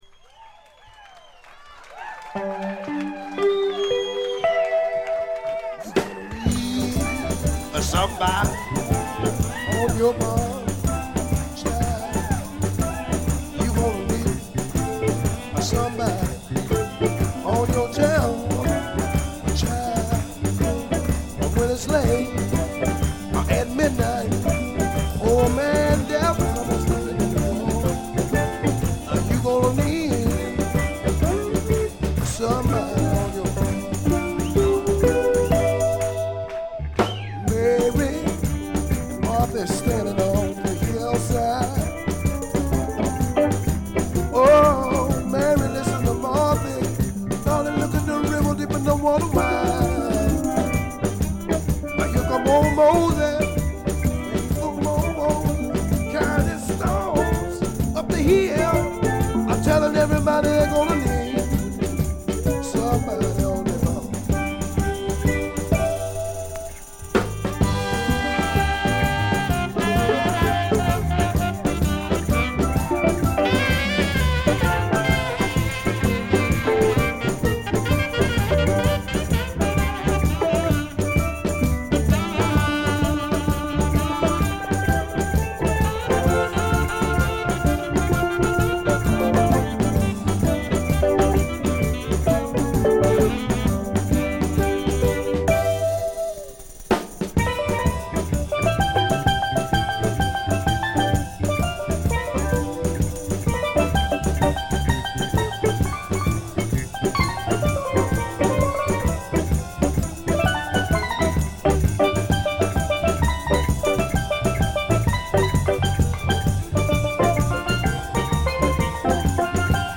ほとんどノイズ感無し。
試聴曲は現品からの取り込み音源です。
electric guitar, piano, lead vocals